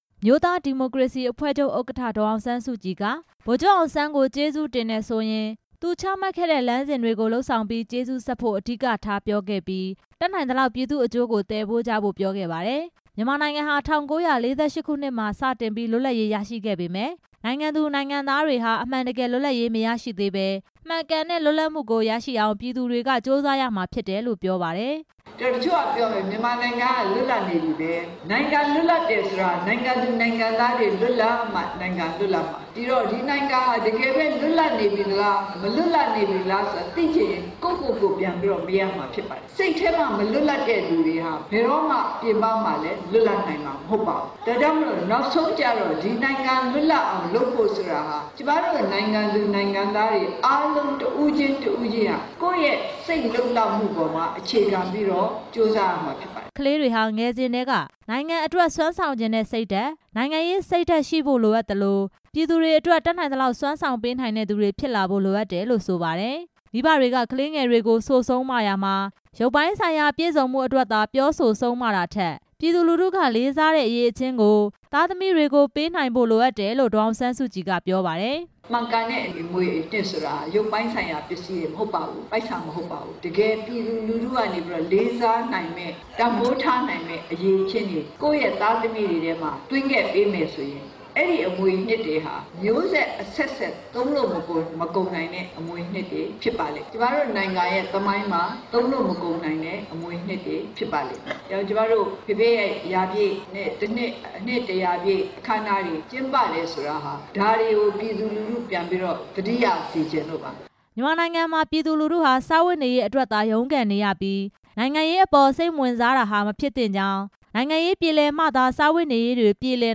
မန္တလေးတိုင်းဒေသကြီး ပျော်ဘွယ်မြို့နယ် ရွှေပြည်သာဘုရားဝင်းမှာ ဗိုလ်ချုပ်နှစ်တစ်ရာပြည့် မွေးနေ့အကြိုလူထုဟောပြောပွဲကို အမျိုးသားဒီမိုကရေစီအဖွဲ့ချုပ် ဥက္ကဌ ဒေါ်အောင်ဆန်းစုကြည် တက်ရောက်ဟောပြောခဲ့ပါတယ်။